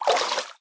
swim1.ogg